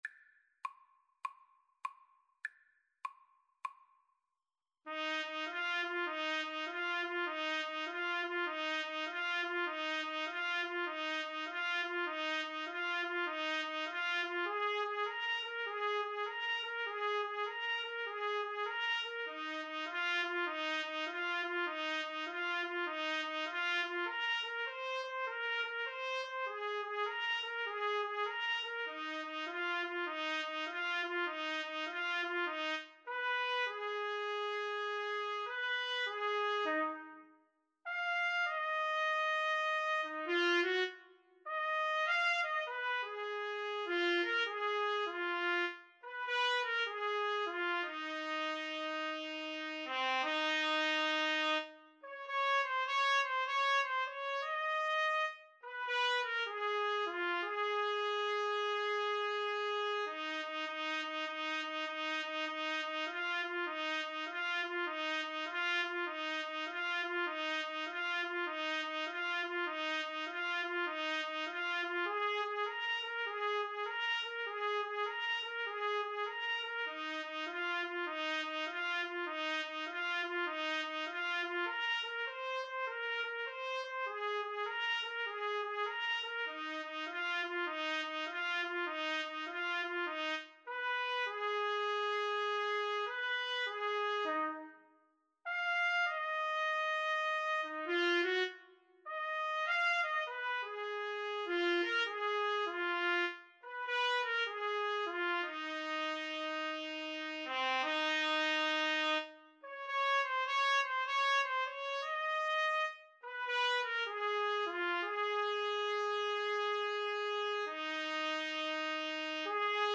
Trumpet Duet version
a popular American 12-bar blues song
Moderato